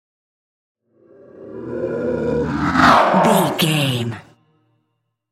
Creature whoosh horror
Sound Effects
Atonal
ominous
eerie
whoosh